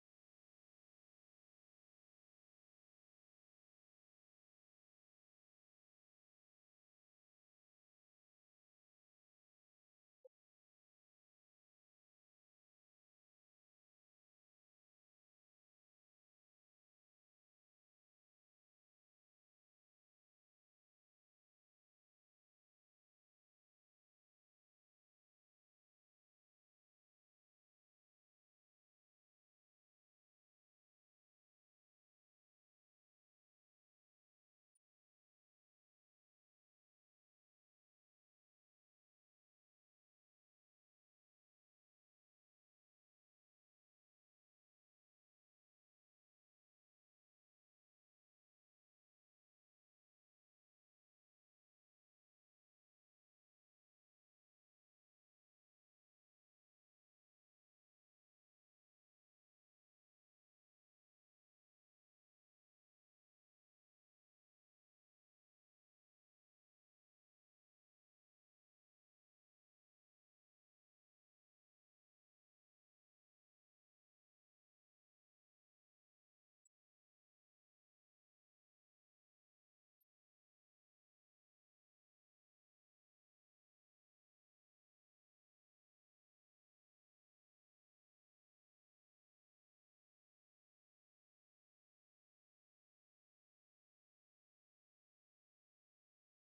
ライブ・アット・パレ・デ・スポーツ、カーン、フランス 02/22/1988
海外マニアによるリマスター音源盤！！
※試聴用に実際より音質を落としています。